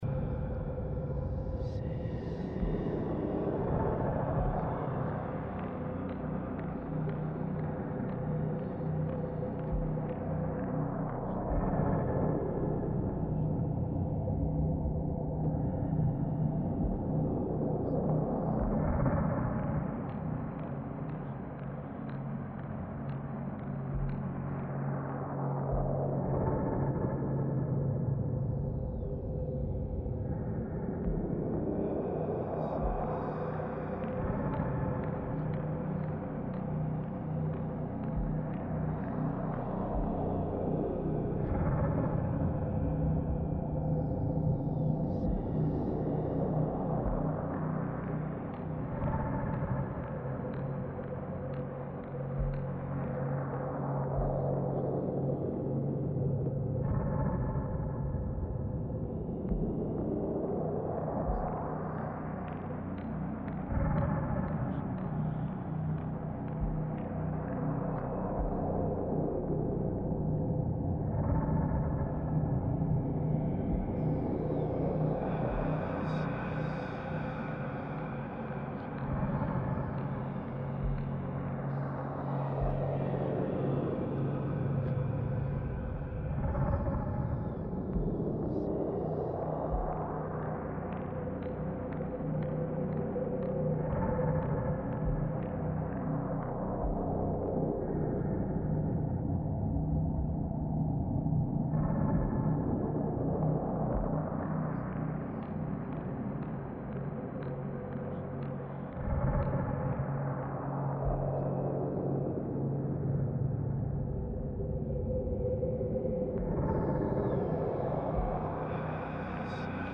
Short Loops Patreon Exclusive - Shadowfell Castle Indoor.mp3